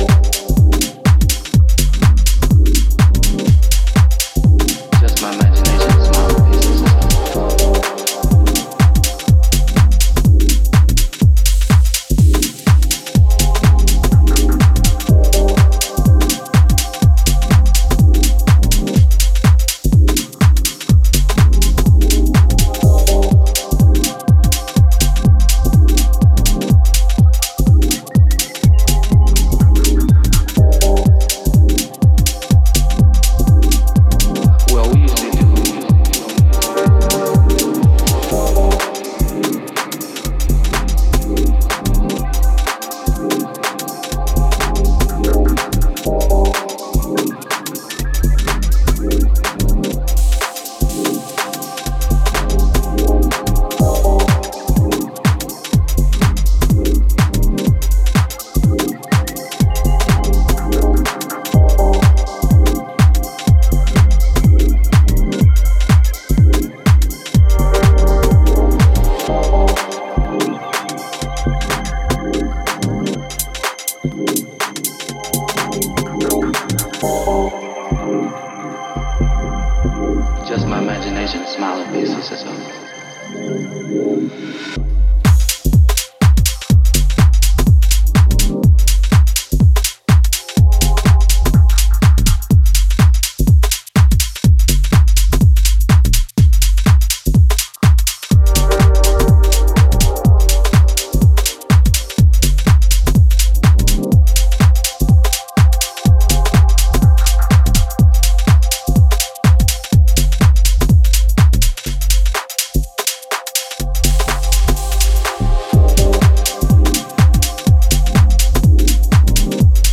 Inspired by the early white label house music releases.